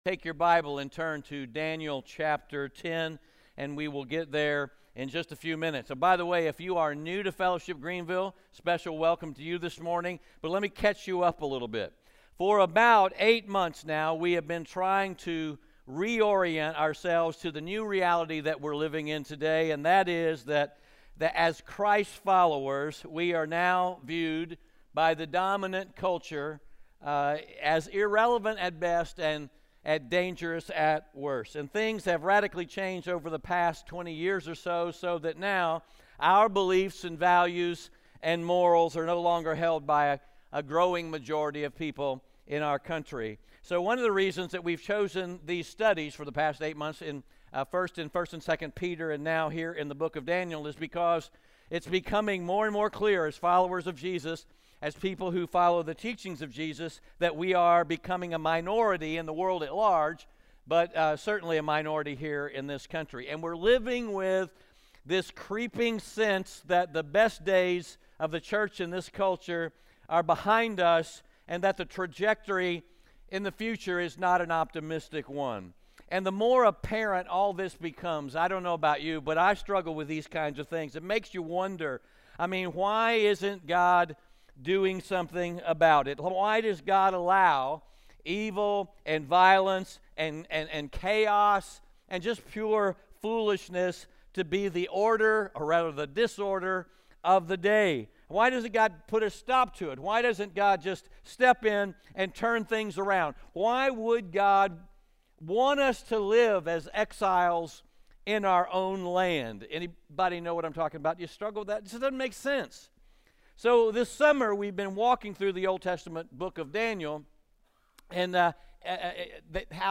Daniel 10-12 Audio Sermon